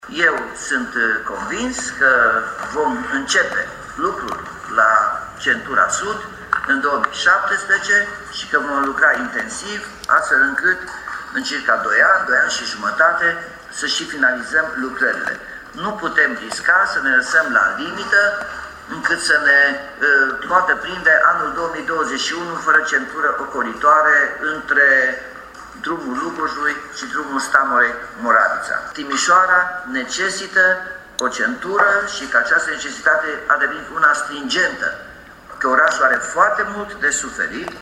Edilul Timișoarei se arată optimist că lucrările vor începe anul viitor: